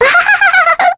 Mirianne-vox-Happy1_kr.wav